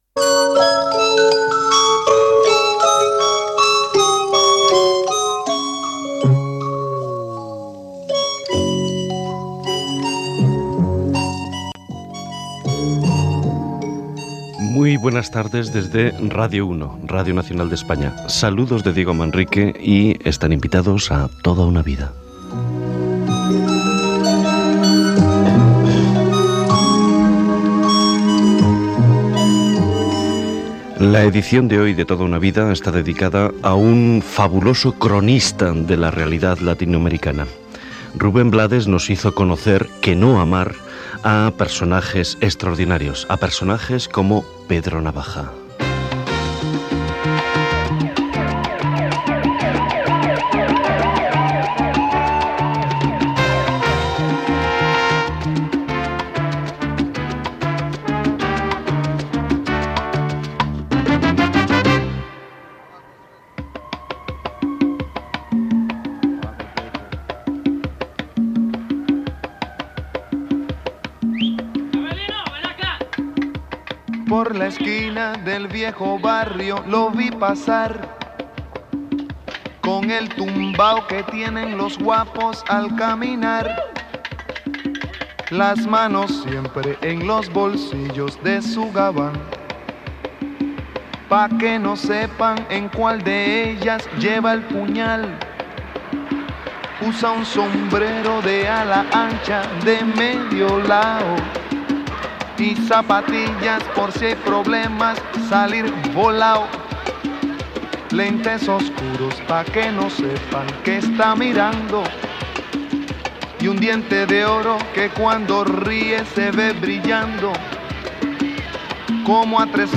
Presentació del programa i entrevista al cantant i compositor Rubén Blades sobre la cançó "Pedro Navaja", la seva feina d'actor i cantant i la societat dels Estats Units